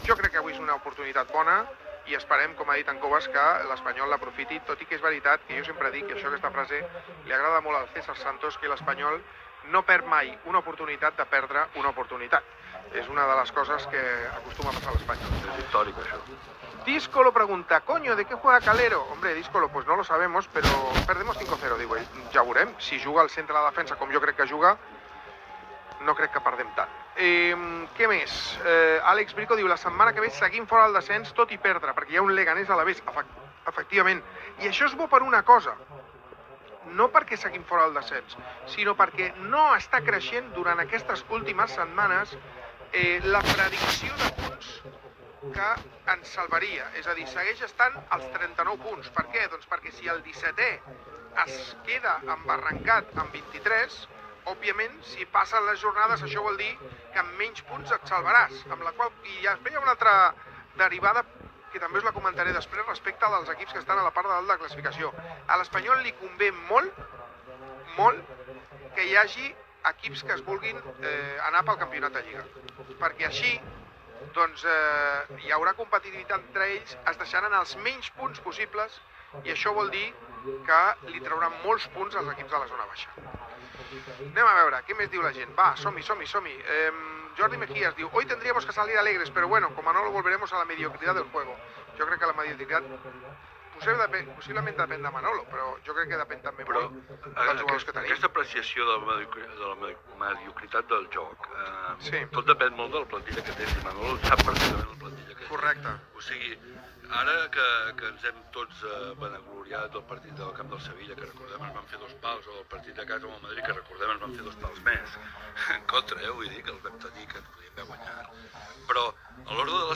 Transmissió del partit de la lliga masculina de futbol entre la Real Sociedad i el R.C.D.Espanyol. Valoració del partit, preguntes de l'audiència, canvi en l'aliniació de l'Espanyol, publicitat, aliniacions dels equips i valoracions, narració de les primeres jugades del partit i gol de la Real Sociedad.